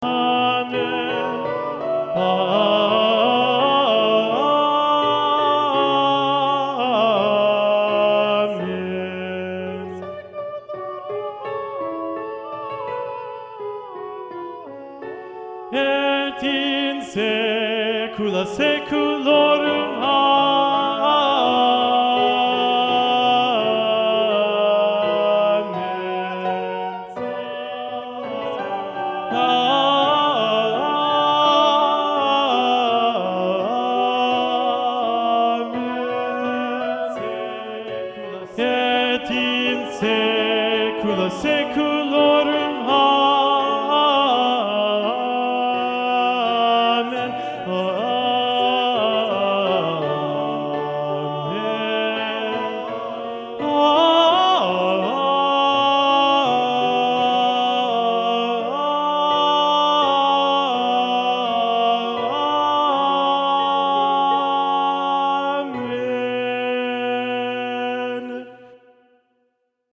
Tenor learning track
domine_tenor.mp3